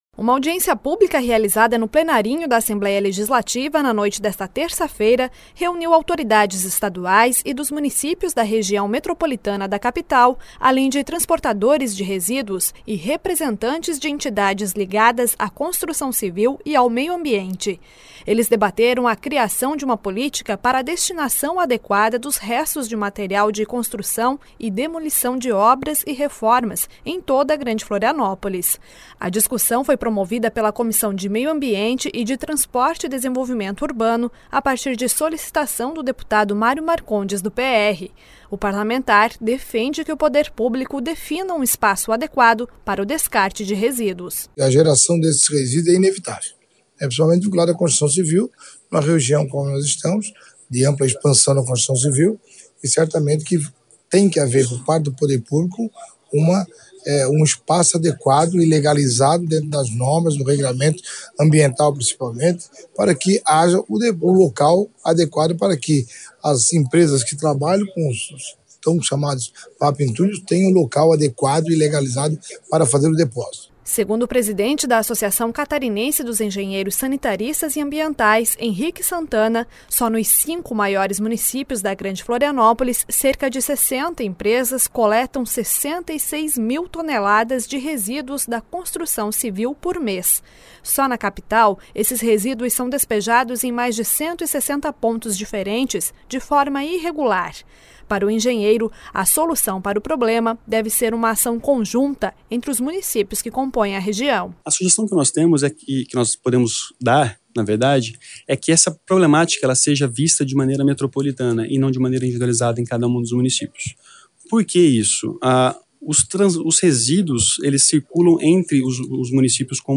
Uma audiência pública realizada no Plenarinho da Assembleia Legislativa na noite desta terça-feira (27), reuniu autoridades estaduais e dos municípios da região metropolitana da Capital, transportadores de resíduos e representantes de entidades ligadas à construção civil e ao meio ambiente.
Entrevistas com: Deputado Mário Marcondes (PR).
Deputado Gean Loureiro (PMDB), presidente da Comissão de Meio Ambiente e Turismo. Deputado João Amin (PP), presidente da Comissão de Transporte e Desenvolvimento Urbano do parlamento.